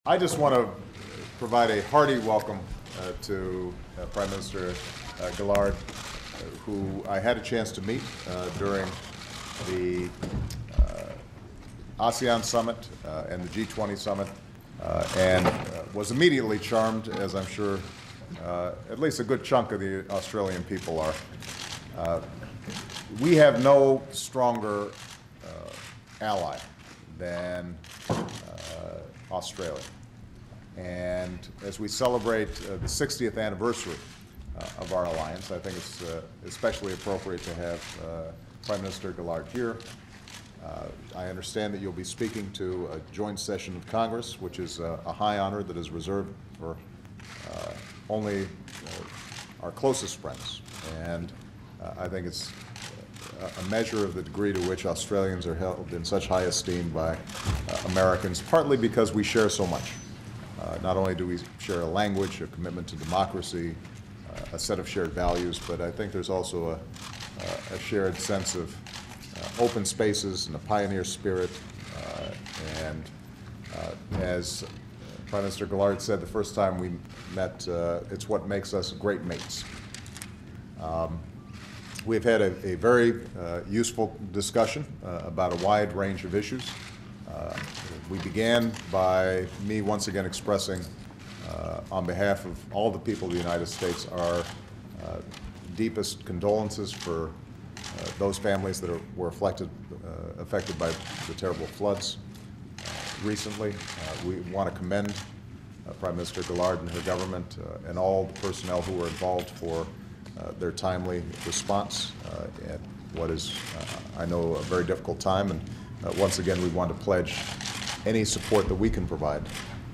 U.S. President Barack Obama and Australian Prime Minister Julia Gillard speak to the press after their meeting